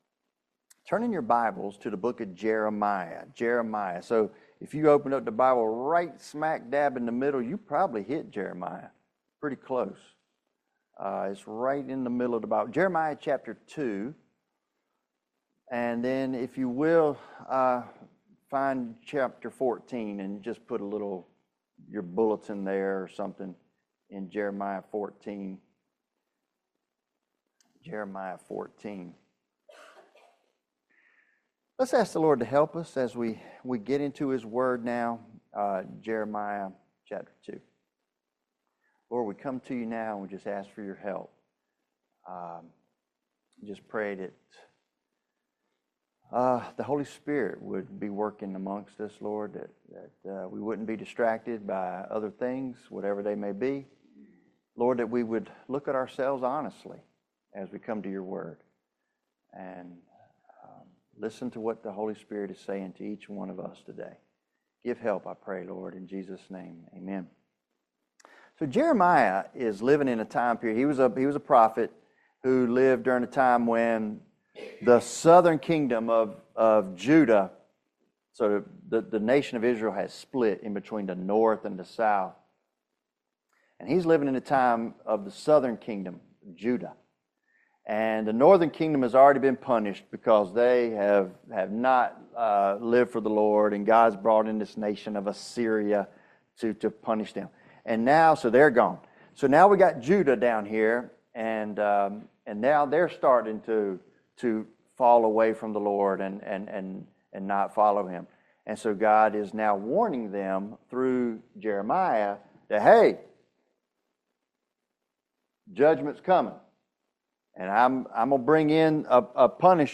Jeremiah 2 Service Type: Family Bible Hour Forsaking God leads to the emptiness of a broken cistern.